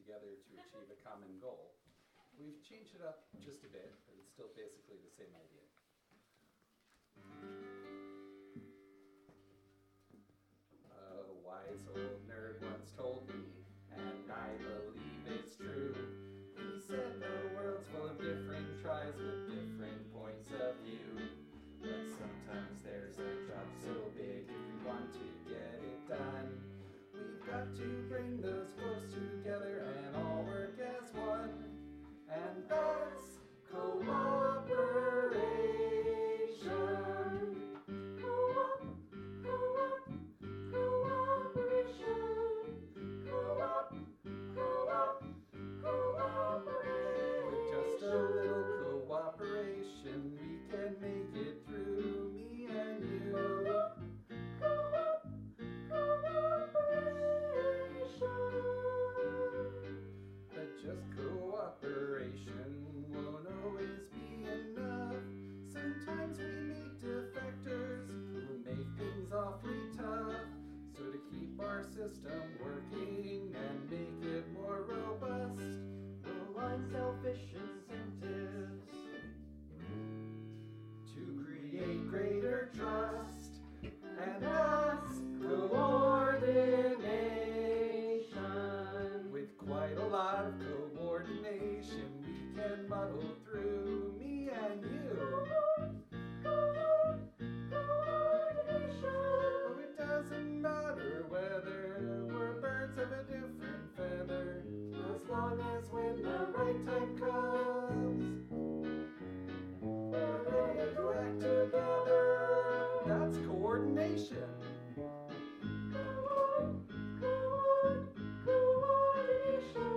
A recording from DC Solstice 2024 is available